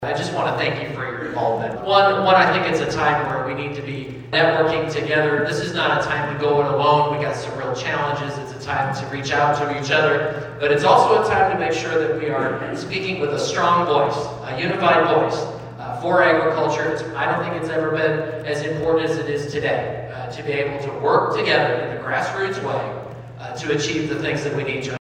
Iowa Secretary of Agriculture was in the listening area earlier this week and served as the keynote speaker for the Carroll County Farm Bureau’s annual meeting and member appreciation dinner. Local ag leaders, Farm Bureau members, and others from the industry gathered Tuesday evening at the Arcadia Legion Hall for the event. Naig says the sector has had a challenging few years.